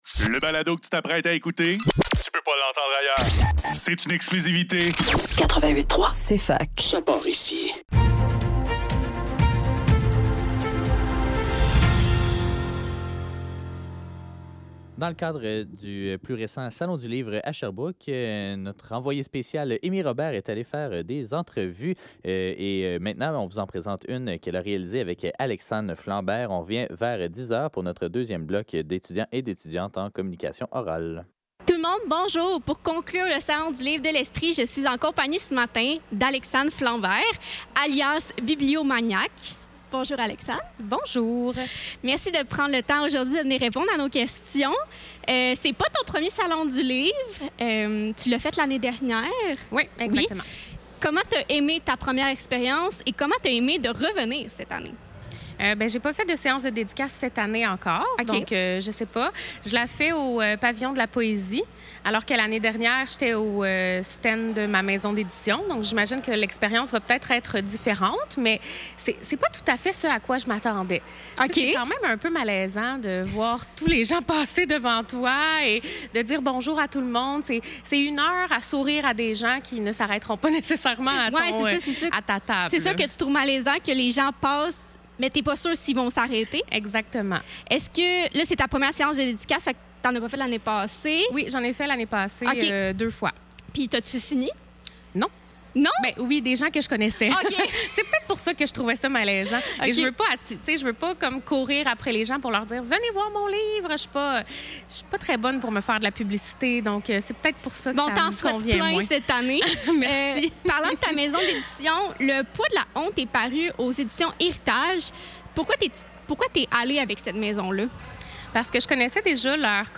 Le neuf - Salon du livre de l'Estrie: Entrevue